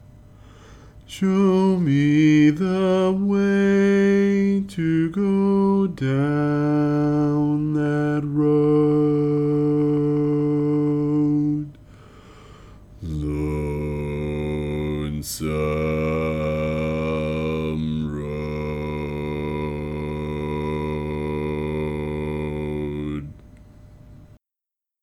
Key written in: D Major
Type: Barbershop
Each recording below is single part only.